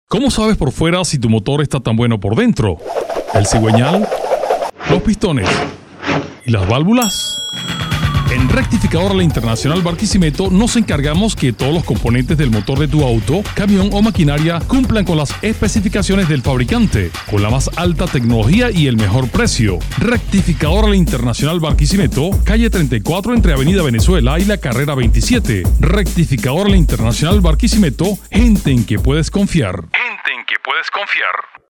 Masculino
Espanhol - Venezuela
spot radio